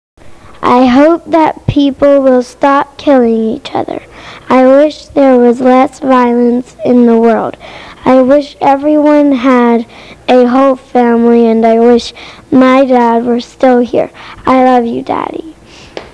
North Carolina observes National Crime Victims Rights Week April 29, 1999 with a ceremony on the grounds of the State Capitol.